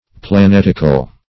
Search Result for " planetical" : The Collaborative International Dictionary of English v.0.48: Planetic \Pla*net"ic\, Planetical \Pla*net"ic*al\, a. [L. planeticus, Gr. ?.] Of or pertaining to planets.